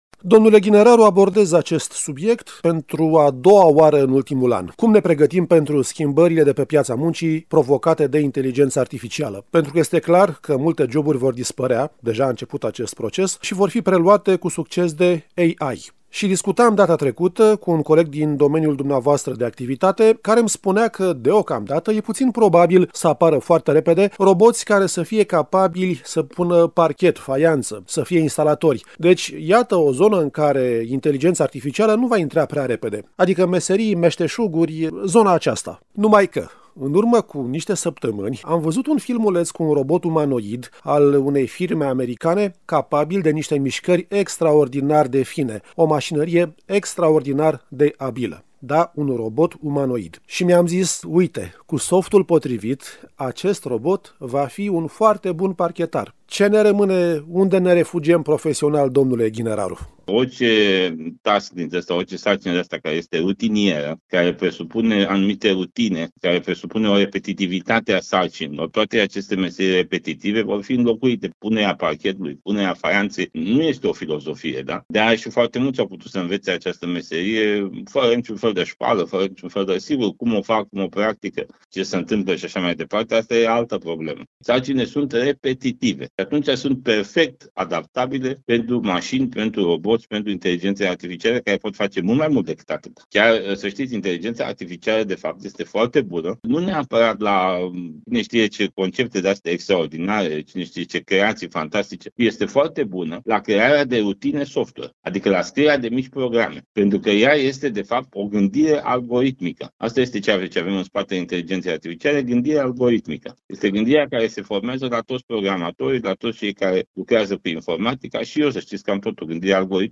Contextul economic dificil pe care îl traversăm și progresul tehnologic impulsionat de inteligența artificială ridică mai multe întrebări, ale căror răspunsuri încercăm să le aflăm, la Radio Constanța, cu ajutorul specialiștilor.